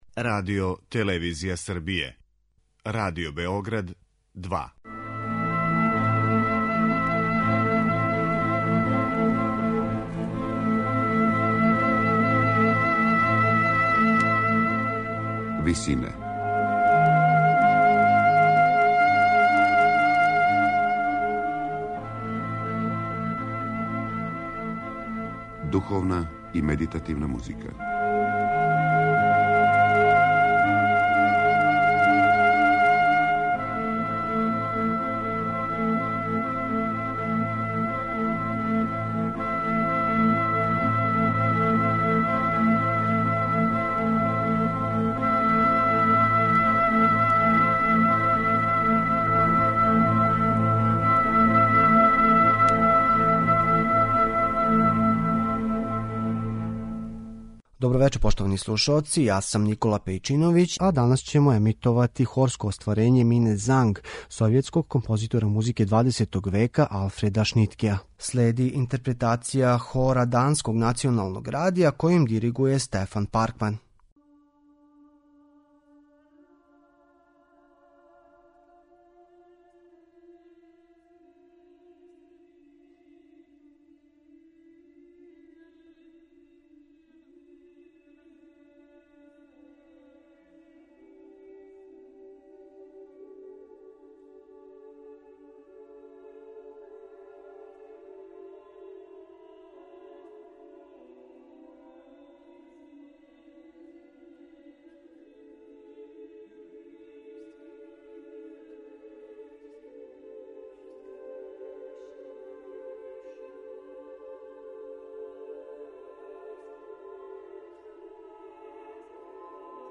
хорско остварење